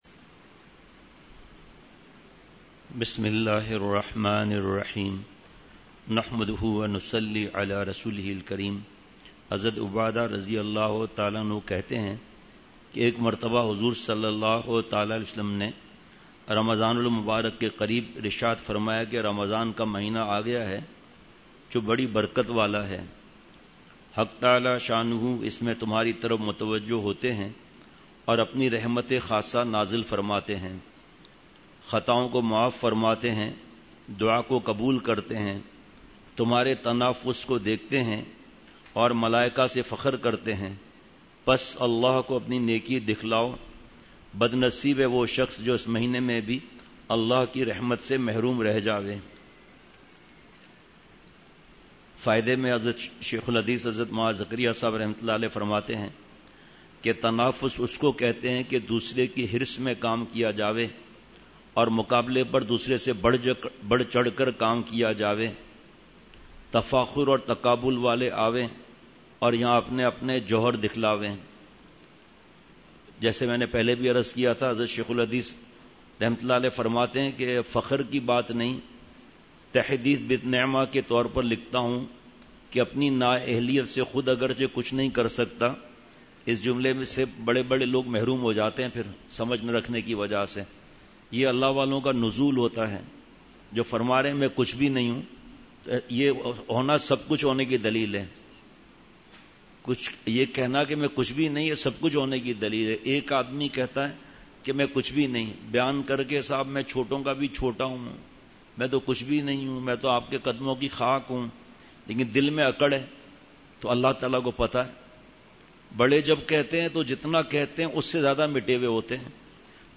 مجلس۹ مئی ۲۰۱۹ء بعد فجر : سب سے بڑا جادو گناہ ہے !